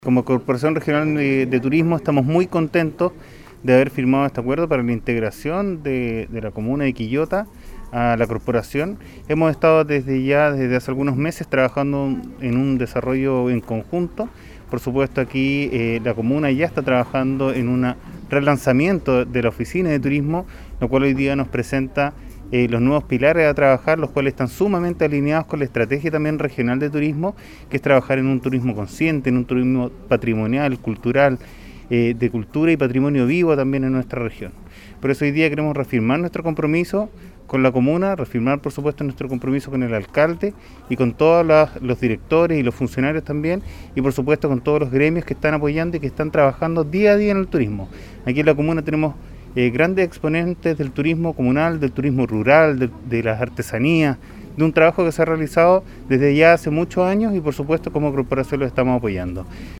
La actividad tuvo lugar en el local de San Pedro de Putupur, Restaurant Promo 385, y contó con la presencia de diferentes autoridades del turismo regional